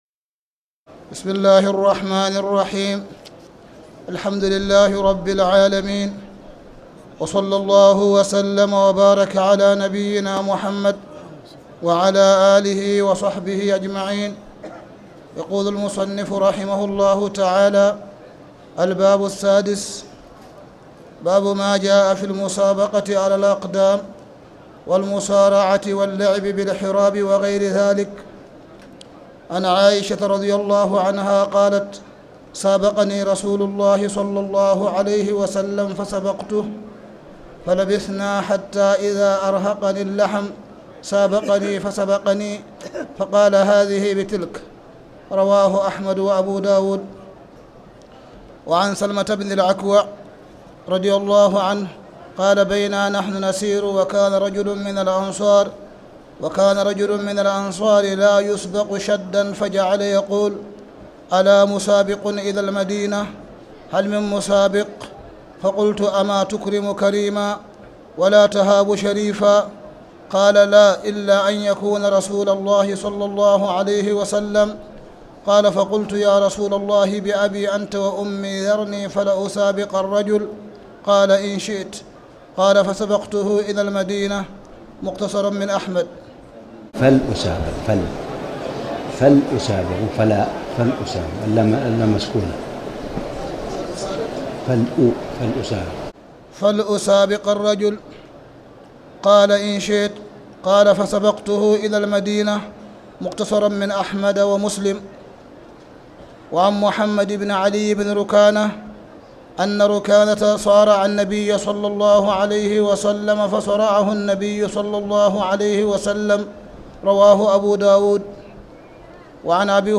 تاريخ النشر ١١ رمضان ١٤٣٨ هـ المكان: المسجد الحرام الشيخ: معالي الشيخ أ.د. صالح بن عبدالله بن حميد معالي الشيخ أ.د. صالح بن عبدالله بن حميد باب ما جاء في المسابقة على الأقدام